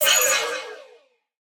Minecraft Version Minecraft Version 1.21.5 Latest Release | Latest Snapshot 1.21.5 / assets / minecraft / sounds / mob / allay / item_taken3.ogg Compare With Compare With Latest Release | Latest Snapshot
item_taken3.ogg